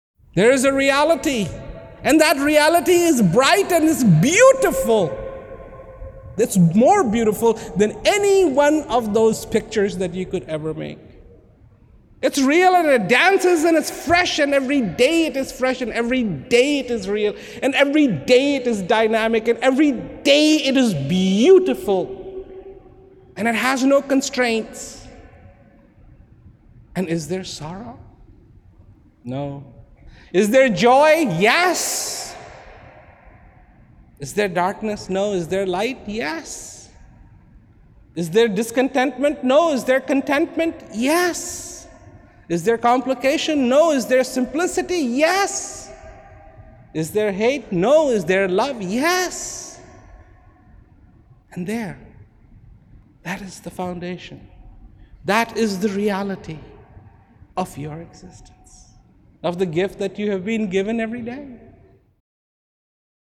Transcripts of Rawat's speeches cannot do justice to Rawat's astonishing delivery, they have to be heard to be appreciated. Mp3 copies of these excerpts are recorded at high quality (256Kbps) to ensure no whispered nuance or frenzied climax is missed.